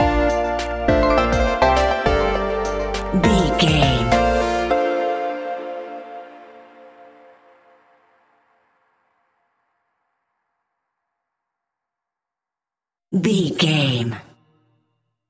Aeolian/Minor
D
hip hop
chilled
laid back
hip hop drums
hip hop synths
piano
hip hop pads